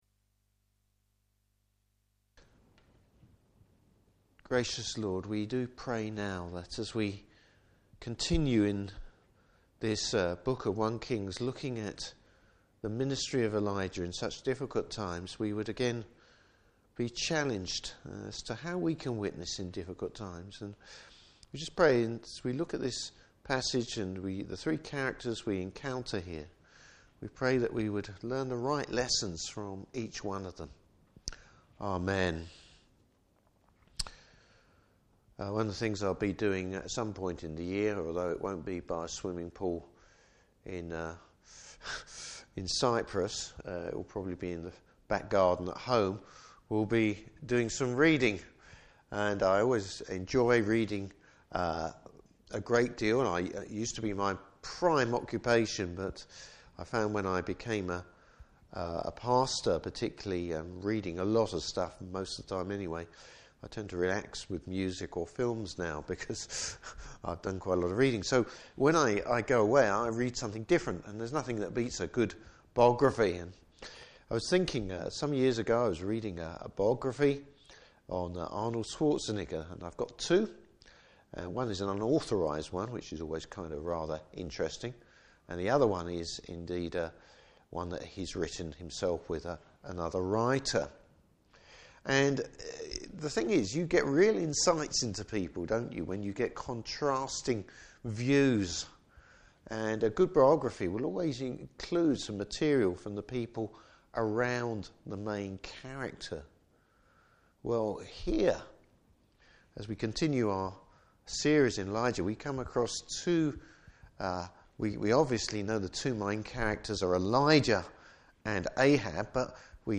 Service Type: Evening Service Bible Text: I Kings 18:1-15.